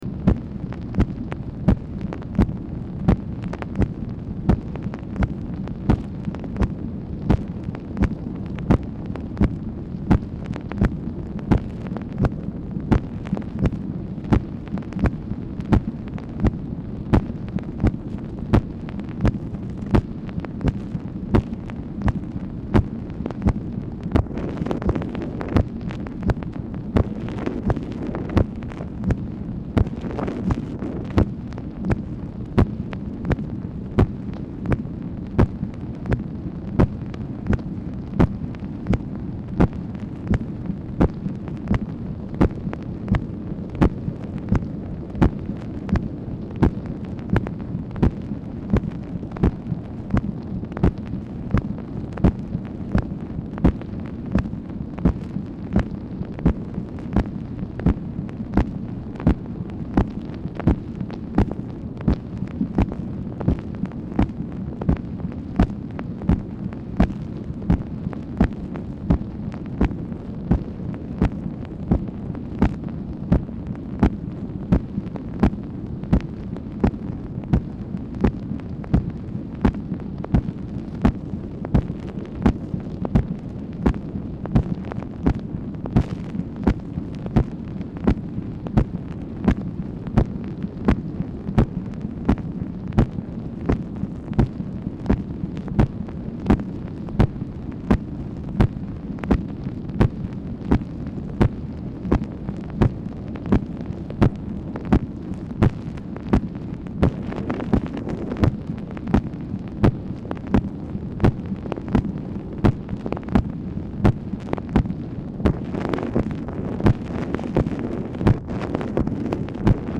Telephone conversation # 8369, sound recording, MACHINE NOISE, 7/21/1965, time unknown | Discover LBJ
Telephone conversation
Format Dictation belt